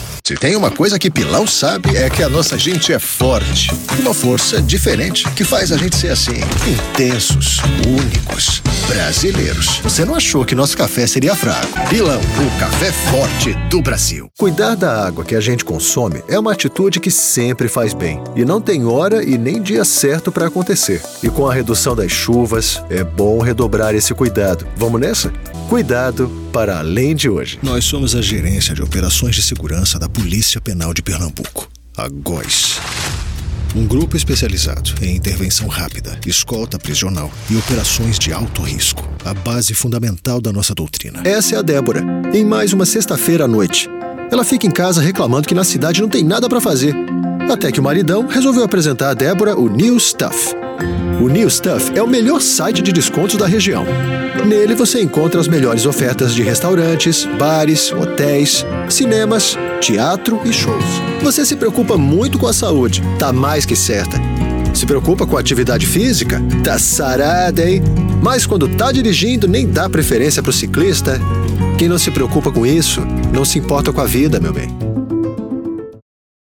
Main Reel - PTBR - commercial natur....mp3